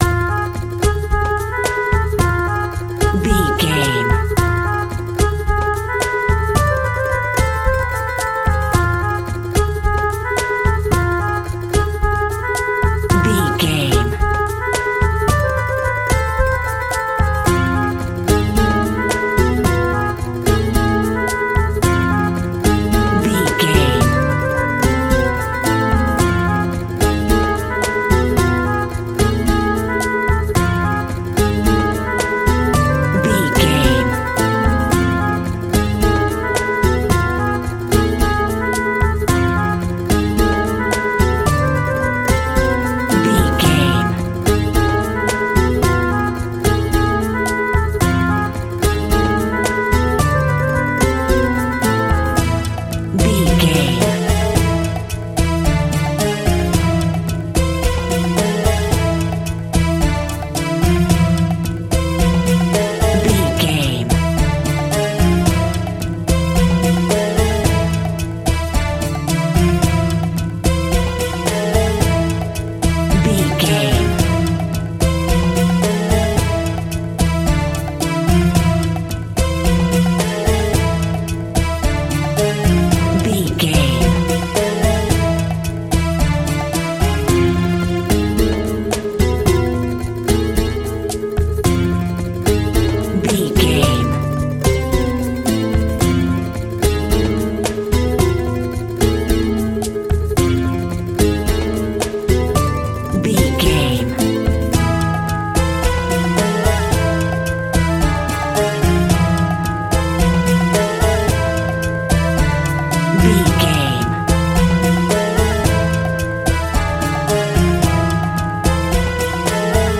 Diminished
sitar
bongos
sarod
tambura